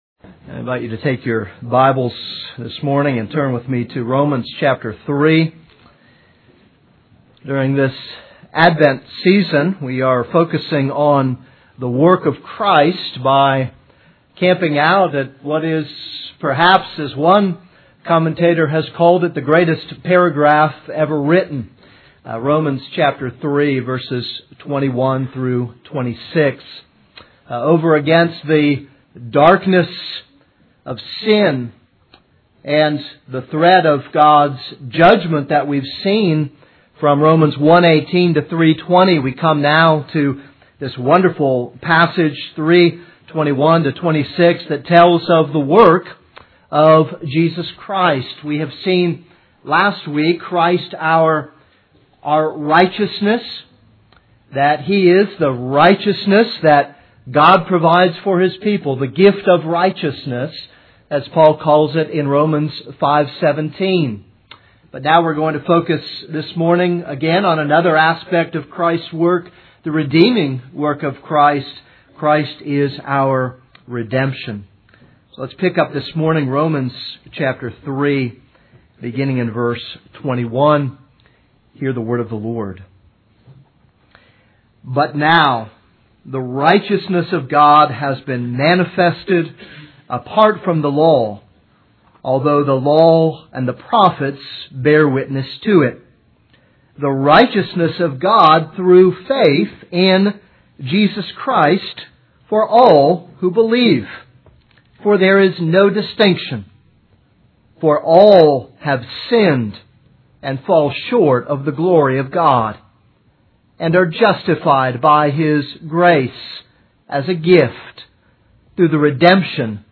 This is a sermon on Romans 3:21-26.